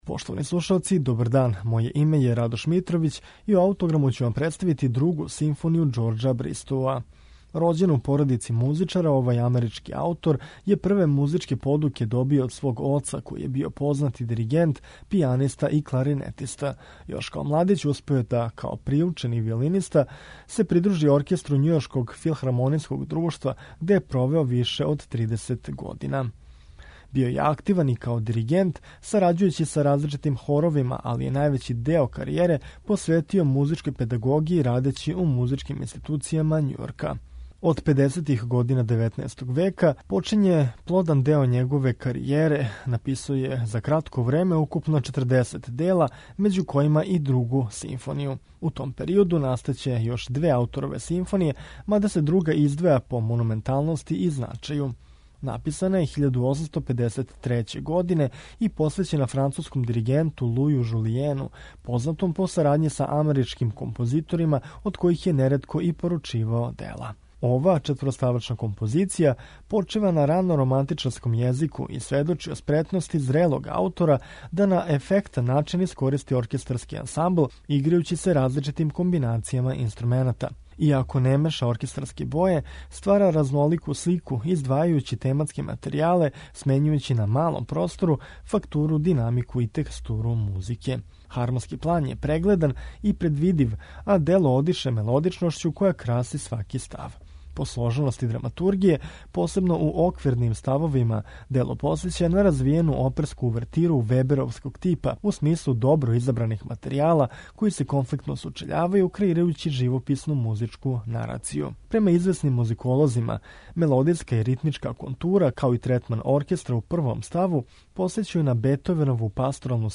Бристоу је написао своју Другу симфонију 1853. године, инспирисан рано романтичарским језиком.
Ово дело ћемо слушати у интерпретацији Северно краљевског симфонијског оркестра